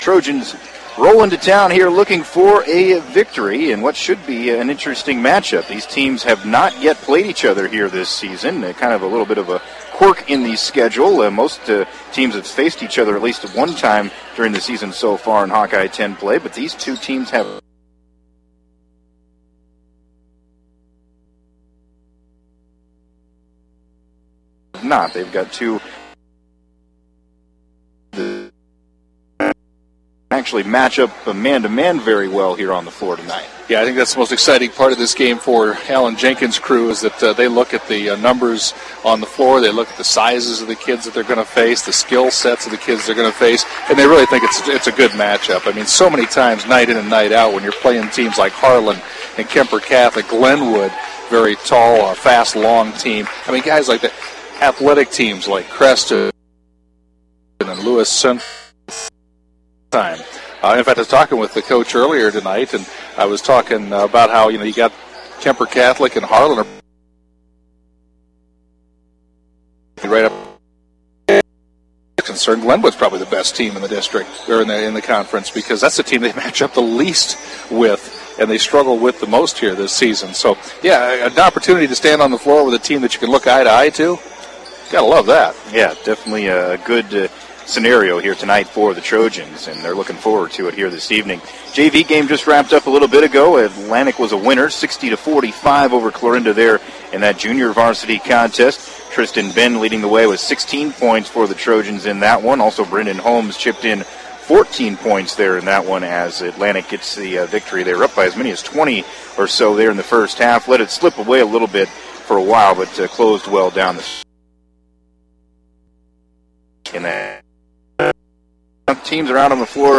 have the call of the game played at Clarinda High School.